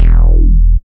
71.08 BASS.wav